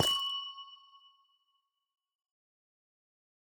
Minecraft Version Minecraft Version 1.21.5 Latest Release | Latest Snapshot 1.21.5 / assets / minecraft / sounds / block / amethyst / step4.ogg Compare With Compare With Latest Release | Latest Snapshot
step4.ogg